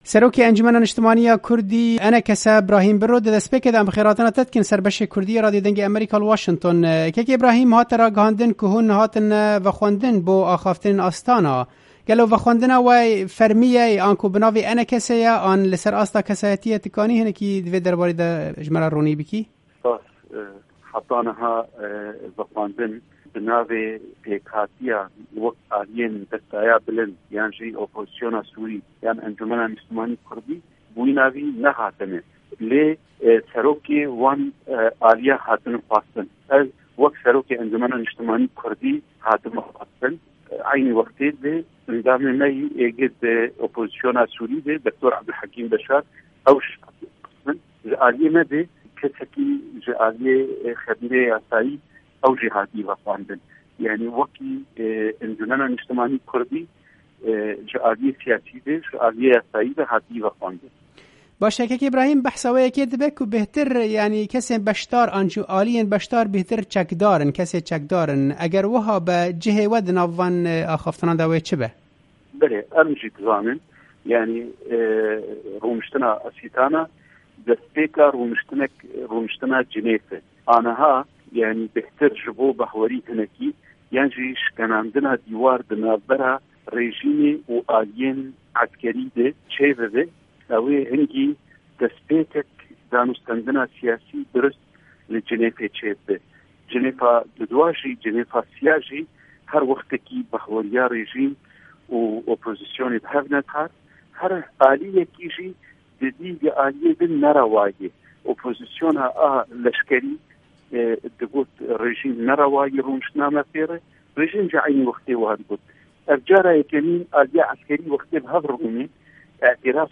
di hevpeyvînekê de li gel Dengê Amerîka ronî da ser vê babetê û got